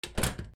豪邸の玄関扉を閉める 弱 01